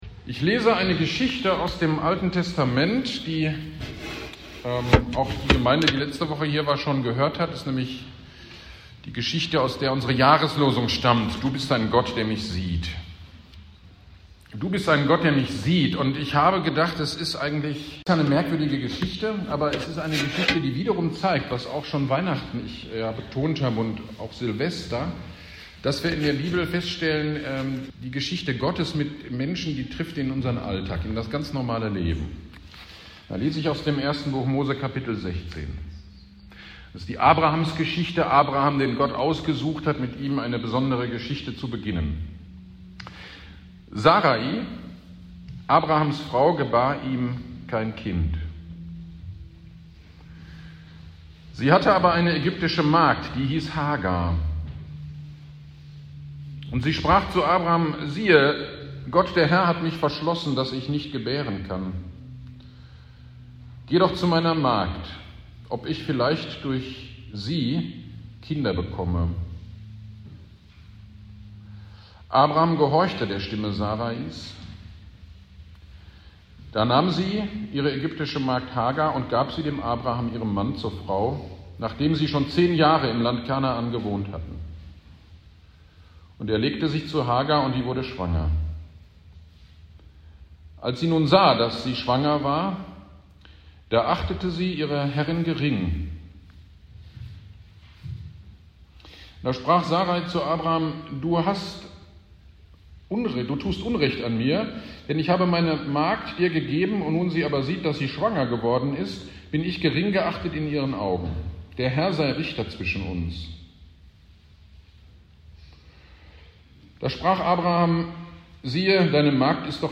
Gottesdienst am 15.01.23 Predigt zur Jahreslosung - Kirchgemeinde Pölzig